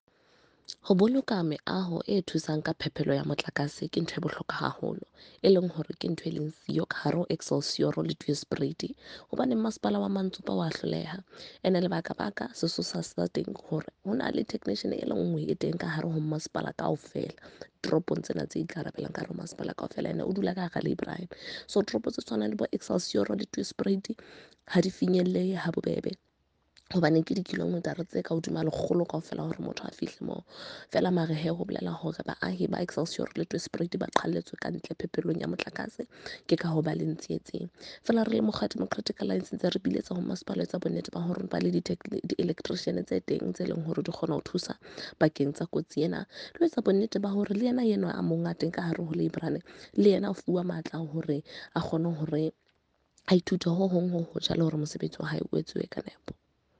Sesotho by Karabo Khakhau MP.
Sotho-voice-Karabo-21.mp3